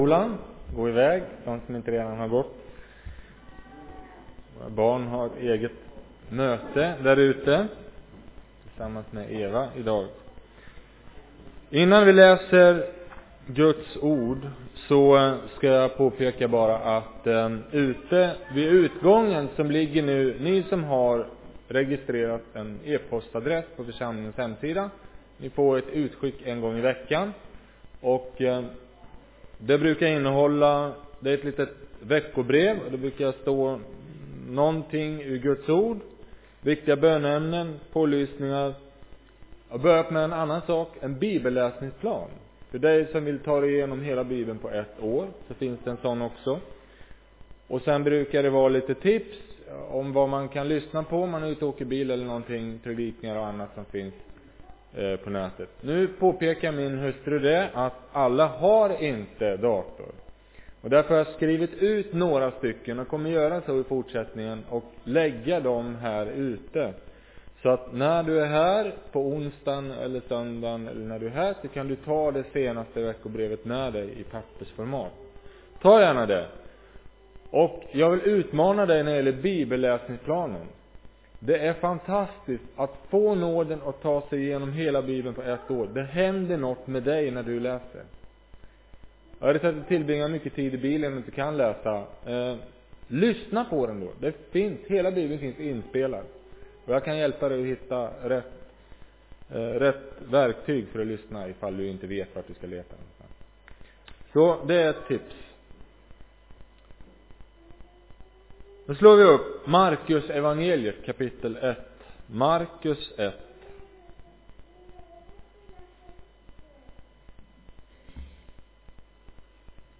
Predikoåret 2016